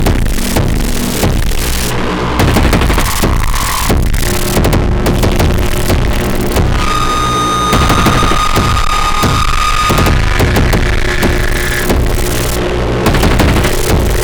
Here are two Syntakt jams using digital and analog tracks (I picked my most recent most noisy things):